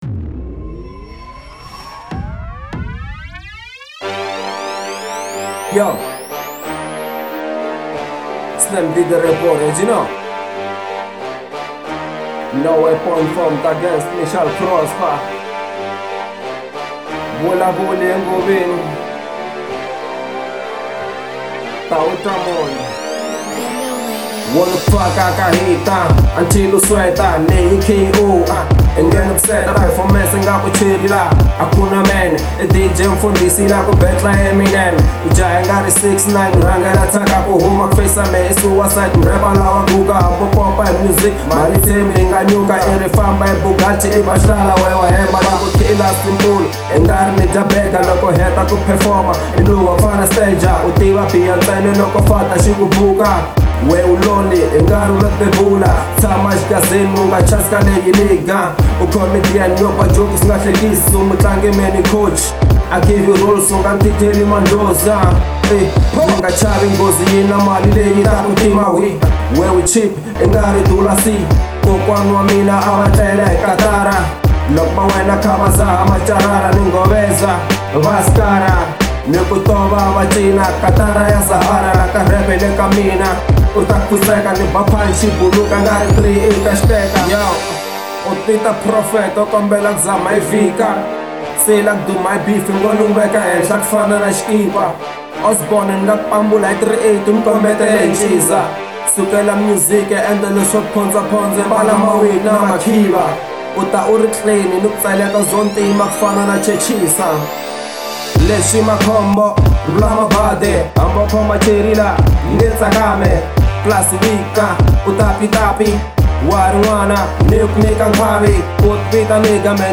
03:13 Genre : Hip Hop Size